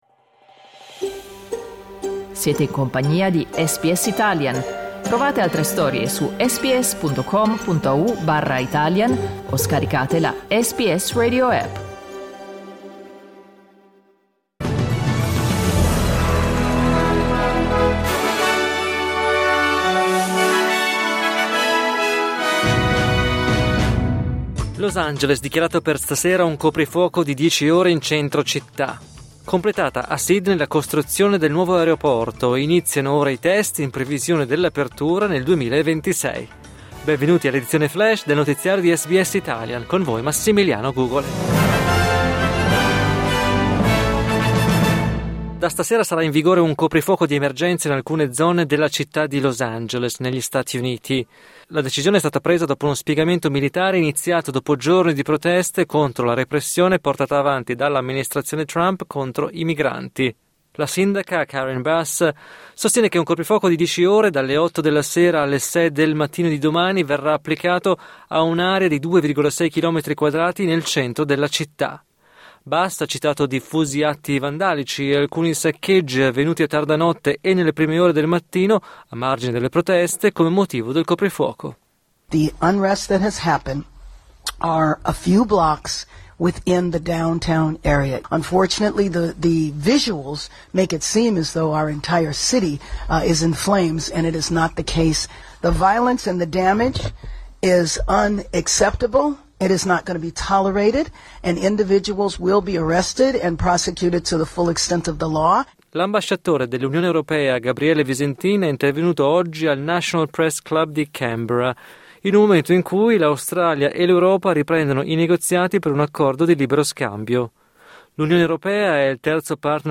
News flash mercoledì 11 giugno 2025